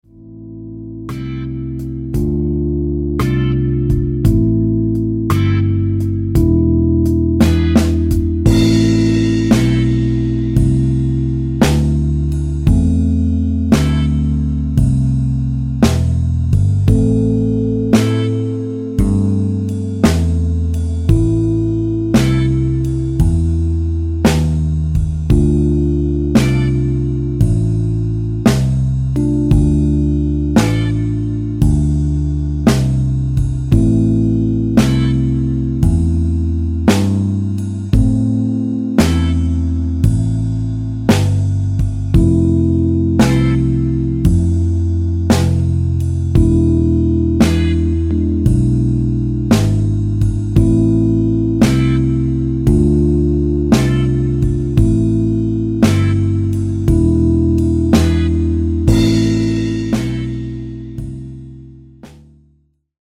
PIu' di 3 settimane per 50 secondi di improvvisazione su un blues minore il LA credo siano necessarie soltanto a chi non è stimolato a partecipare o a chi è totalmente impossibilitato.
Per quanto riguarda la base per registrare (nr. 02), intro e frammento a sfumare del giro successivo sono inseriti per facilitare la valutazione di inizio e fine del proprio solo e semplificare a me il processo di sincronizzazione in caso di note molto ravvicinate tra una parte e l'altra.
slow-blues-jam_record.mp3